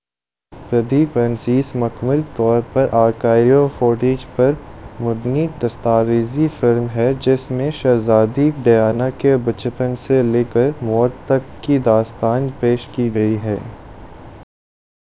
Spoofed_TTS/Speaker_09/273.wav · CSALT/deepfake_detection_dataset_urdu at main